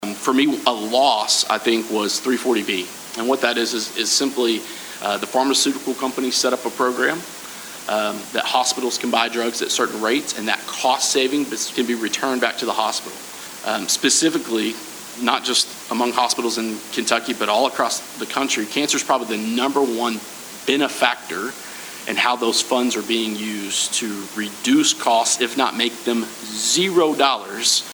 8th District State Representative Walker Thomas, 9th District State Representative Myron Dossett, and 3rd District State Senator Craig Richardson shared some of those Thursday night at a Town Hall.